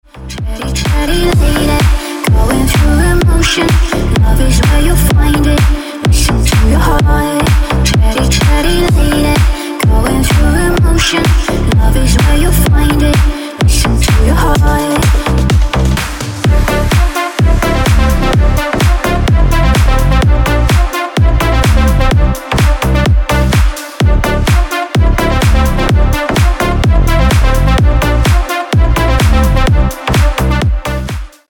• Качество: 320, Stereo
deep house
retromix
женский голос
Electronic
Cover